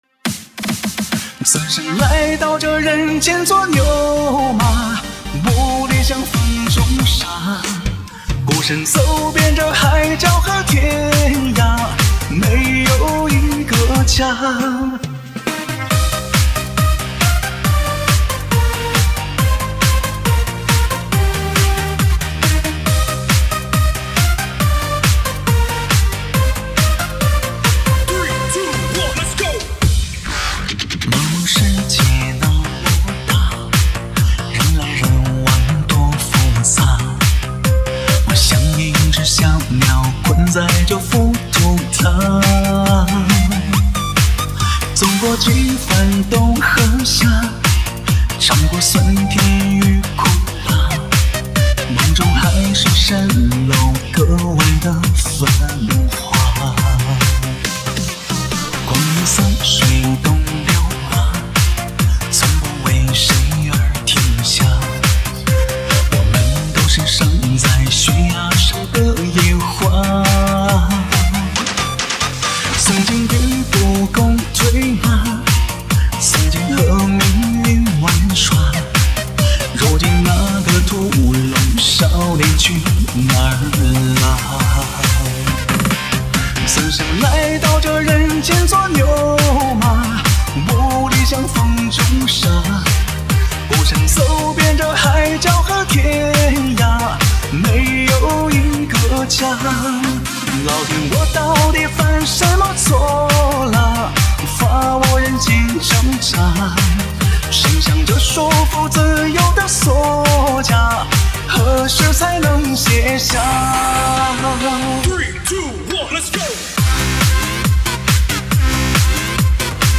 dj舞曲
分类： 交谊舞曲、电音DJ舞曲
车载U盘DJ